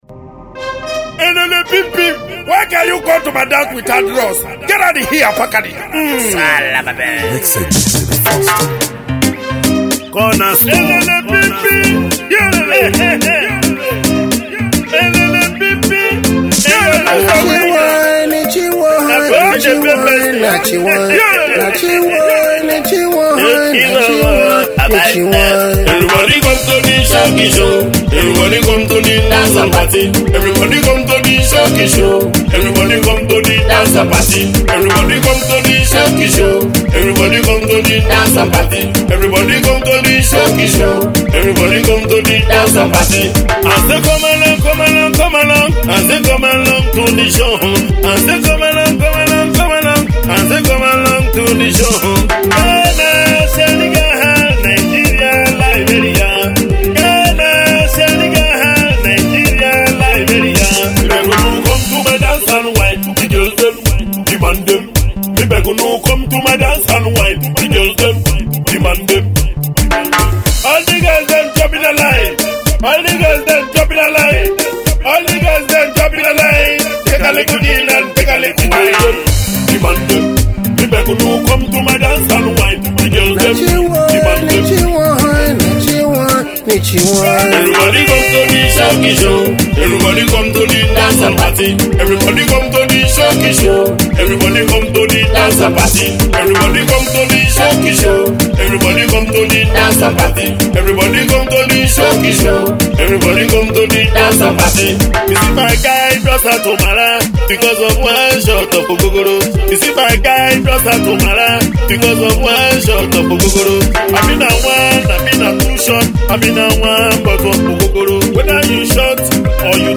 The Galala music messenger has returned!